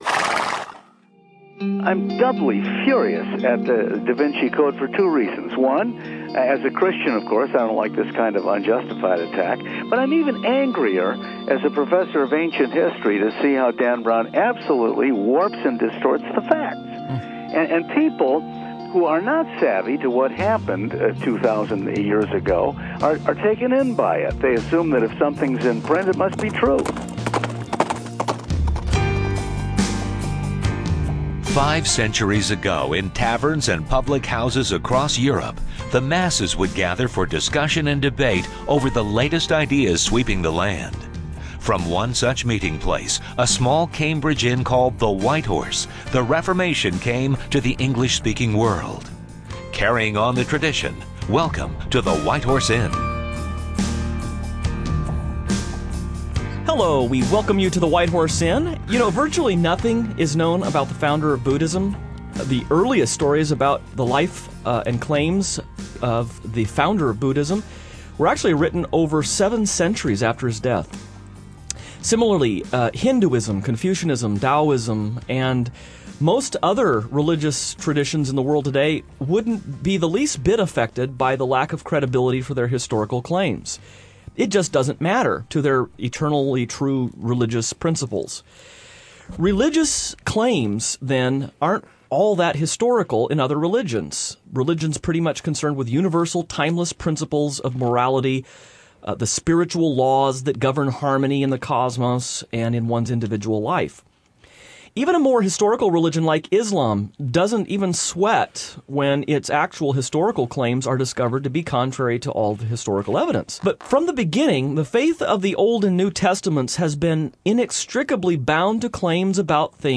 Featuring an interview with history professor